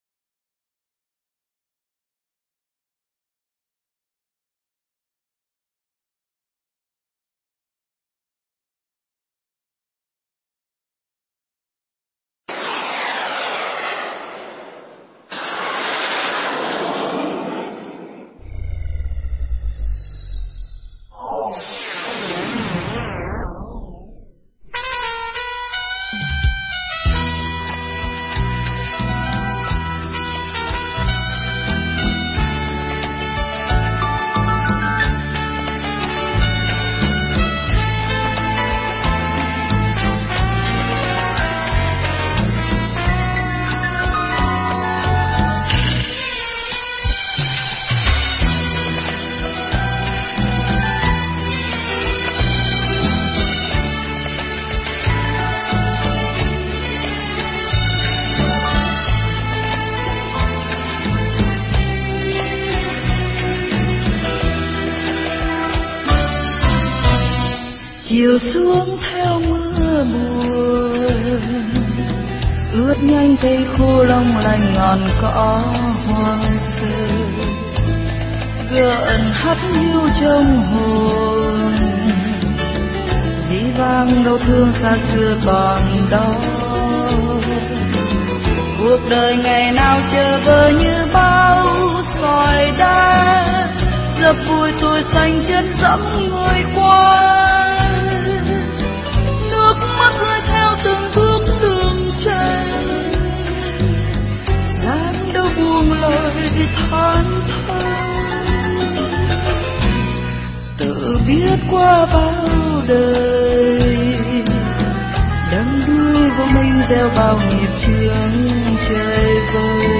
Nghe Mp3 thuyết pháp Quyết Định Vãng Sanh
Mp3 Pháp thoại Quyết Định Vãng Sanh